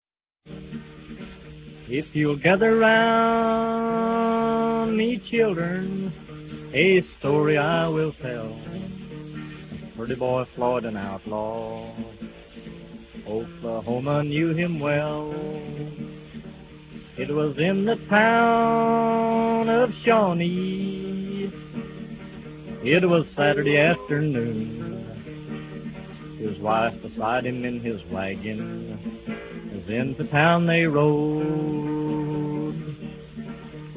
Recorded in New York between 1944 and 1949.